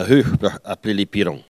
Elle crie pour appeler les oisons
Catégorie Locution